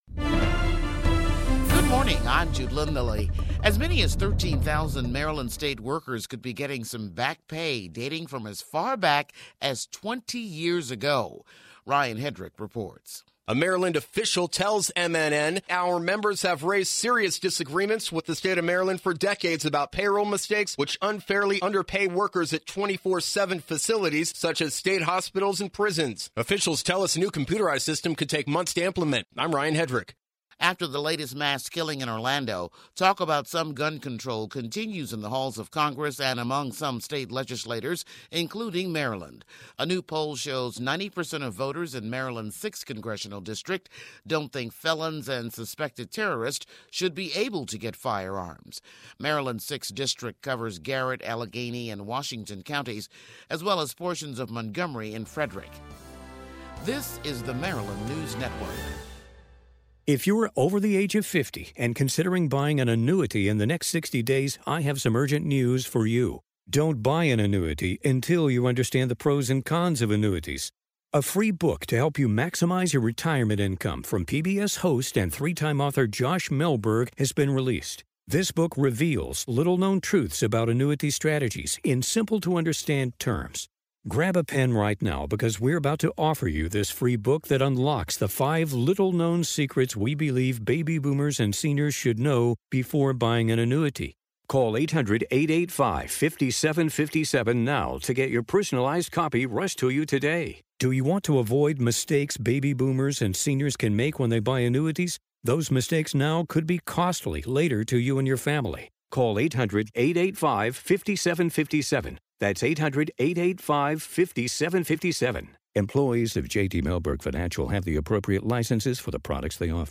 The first newscast from the Maryland News Network features the latest headlines from around the state of Maryland.